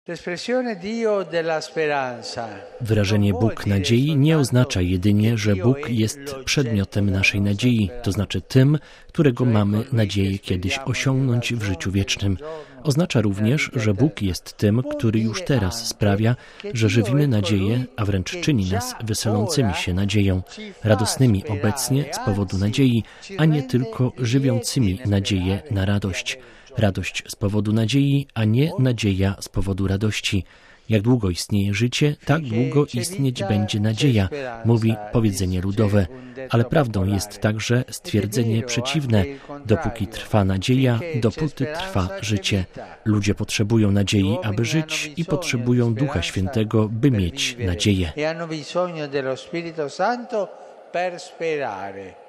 Duch Święty sprawia, że obfitujemy w nadzieję, nigdy się nie zniechęcamy oraz jesteśmy jej siewcami, czyli pocieszycielami i obrońcami braci – mówił Franciszek w czasie audiencji ogólnej.